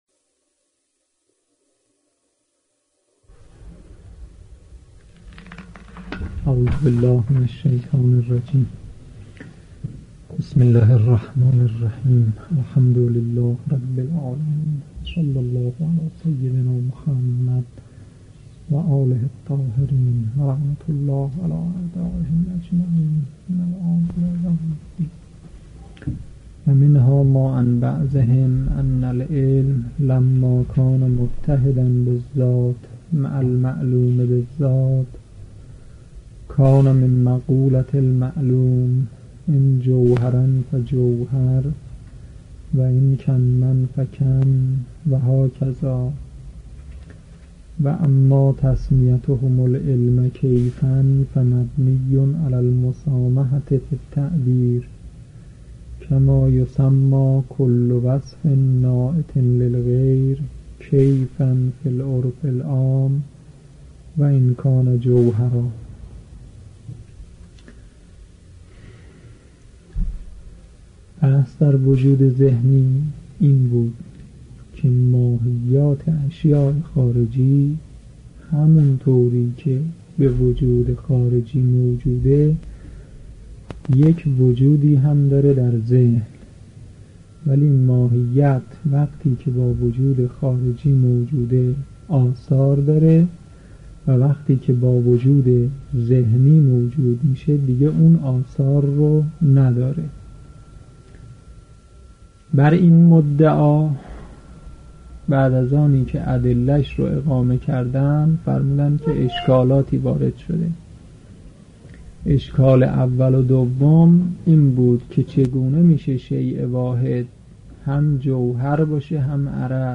در این بخش، کتاب «بدایة الحکمة» که می‌توان دومین کتاب در مرحلۀ شناخت علم فلسفه دانست، به صورت ترتیب مباحث کتاب، تدریس می‌شود.